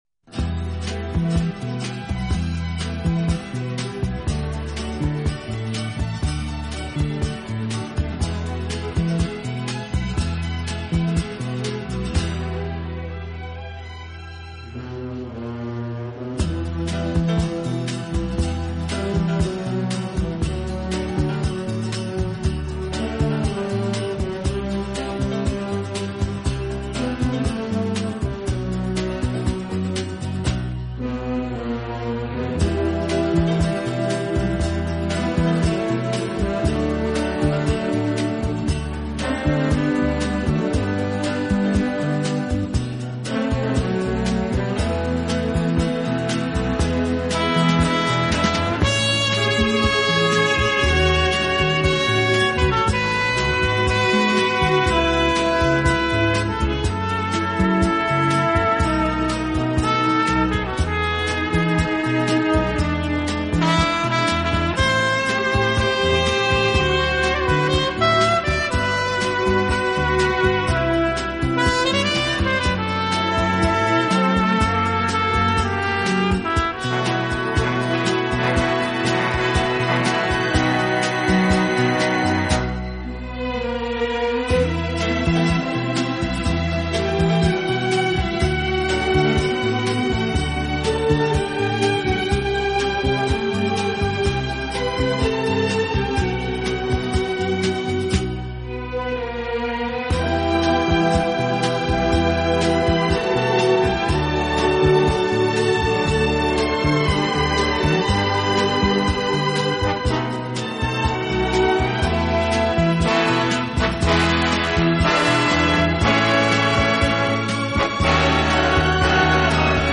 【轻音乐专辑】
录制方式：AAD